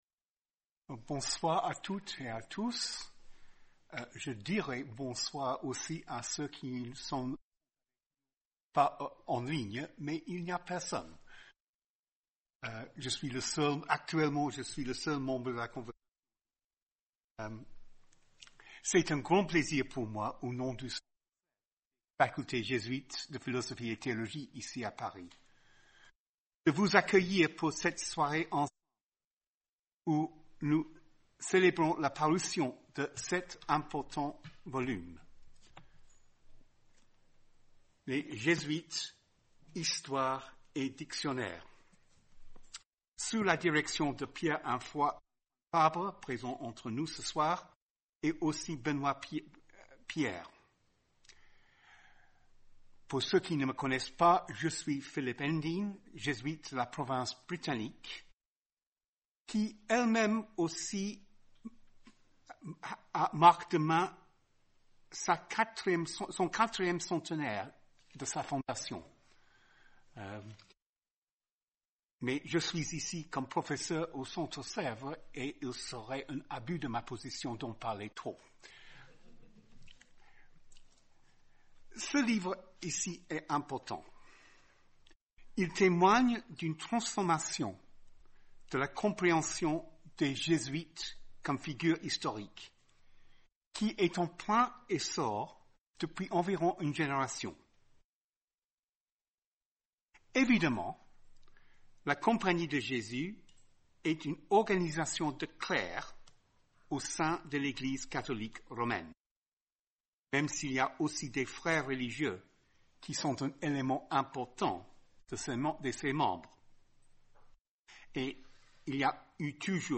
Soirée animée